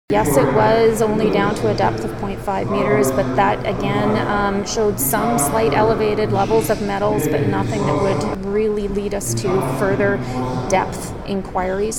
Hundreds of concerned, and at times frustrated, residents packed the Kerry Park Rec Centre on Thursday night to hear about environmental testing from an area around a contaminated soil dump near Shawnigan Lake.